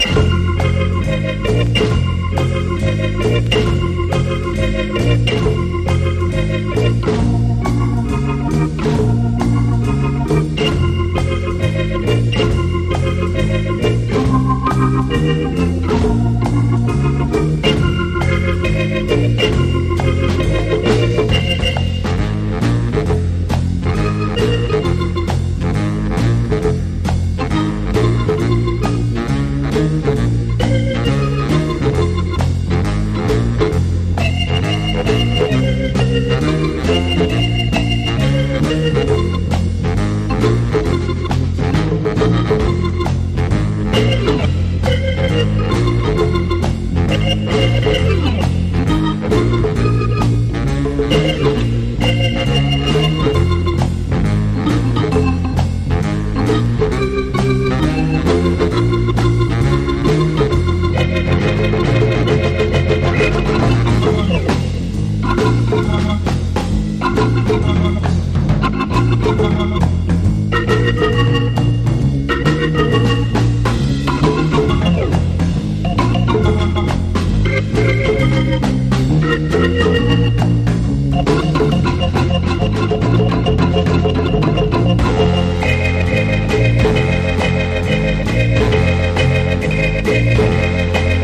モッド・クラシックなキラー・カヴァー多数の1964年録音！